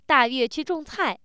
happy